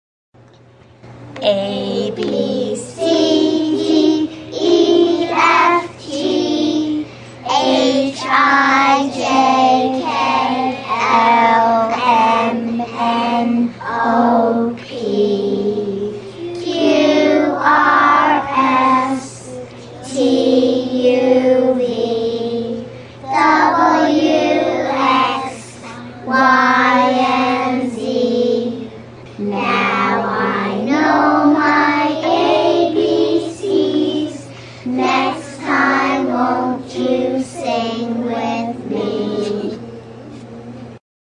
A, B, C Song Chanson enfantine (Anglais) A B C D E F G H I J K L M N O P Q R S T U V W X Y and Z Now I know my ABC's, Next time won't you sing with me!
abcslow2a.mp3